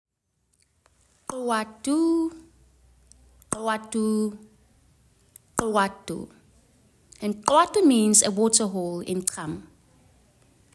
!Khwa ttu Pronunciation